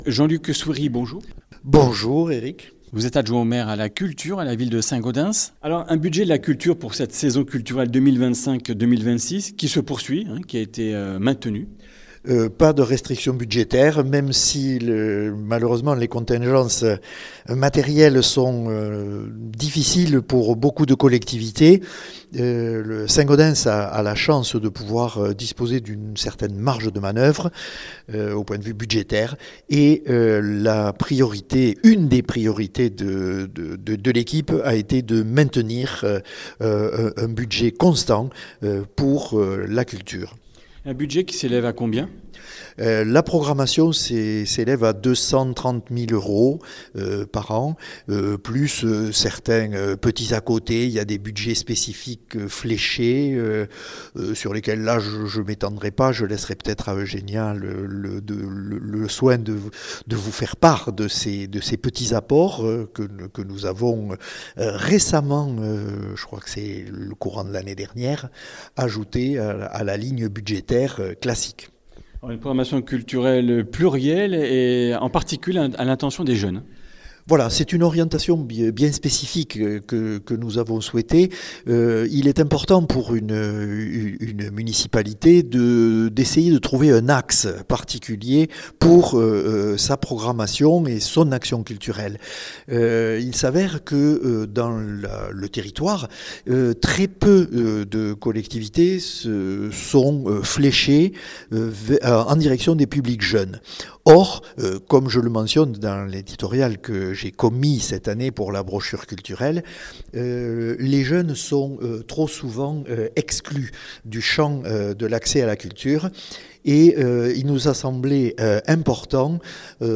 Comminges Interviews du 22 sept.